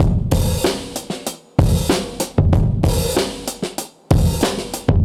Index of /musicradar/dusty-funk-samples/Beats/95bpm/Alt Sound
DF_BeatA[dustier]_95-04.wav